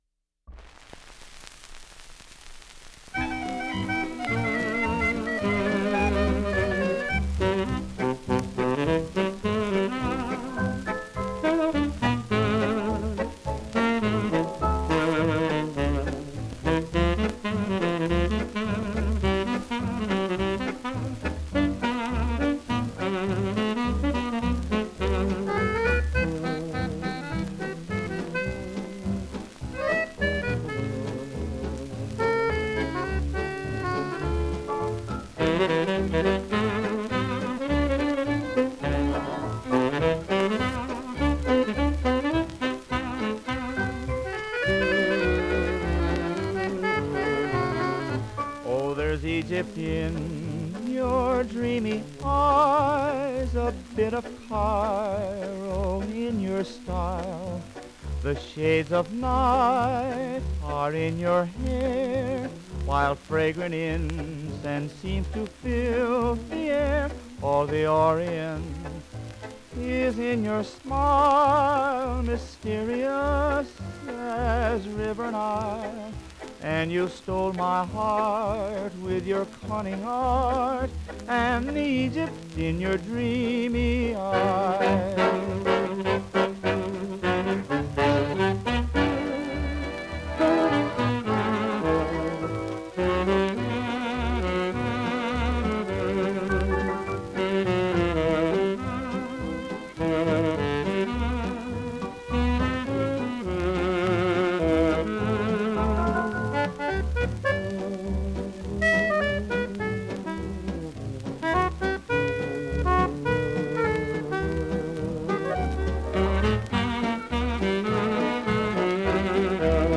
Genre: Fox-Trot.